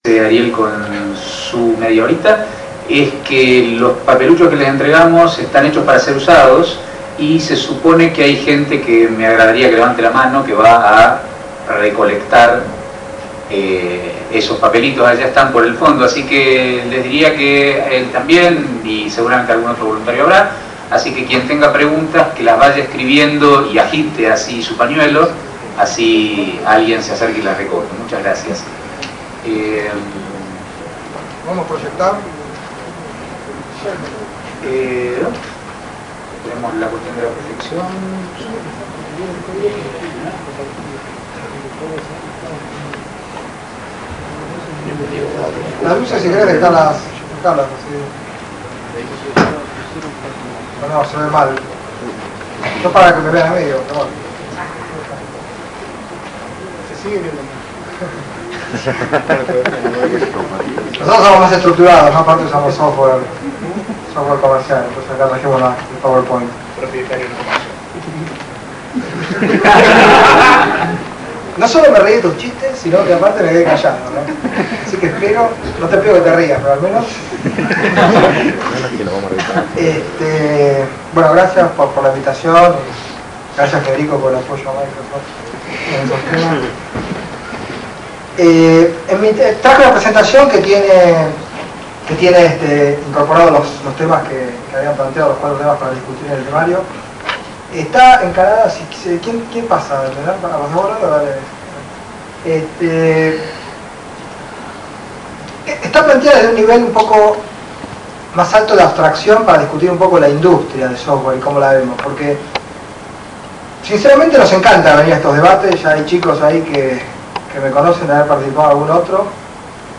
En el marco de la JAIIO (jornadas organizadas por la SADIO) se organizo un debate titulado Software Libre vs. Software Propietario. Por parte del software libre habló gente de la Fundación Via Libre, por parte del software propietario hablo gente de M$.
El debate no se pudo grabar todo aunque solamente se perdieron 5 minutos del comienzo y 10 del final Primero expuso la gente de Via Libre, luego la gente de M$ y después los representantes de cada parte respondieron preguntas del publico (esta es la parte más rica del debate).